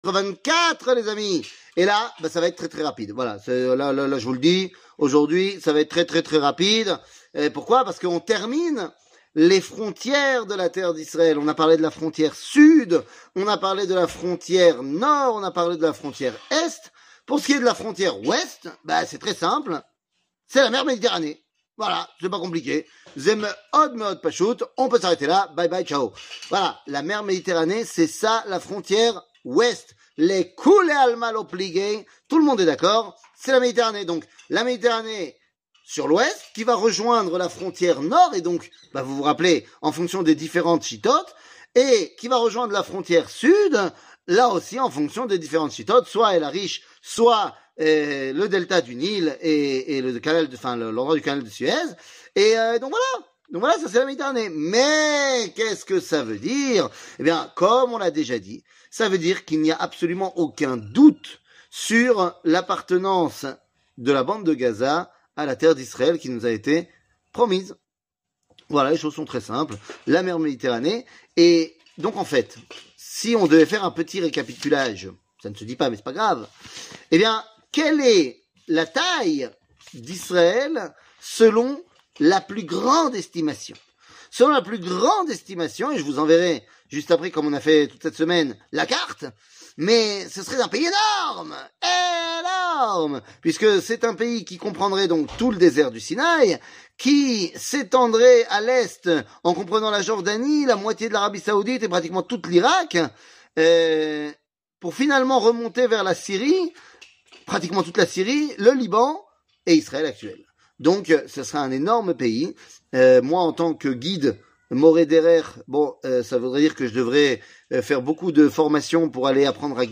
L'éternité d'Israel ne mentira pas ! 24 00:02:47 L'éternité d'Israel ne mentira pas ! 24 שיעור מ 09 נובמבר 2023 02MIN הורדה בקובץ אודיו MP3 (2.54 Mo) הורדה בקובץ וידאו MP4 (4.32 Mo) TAGS : שיעורים קצרים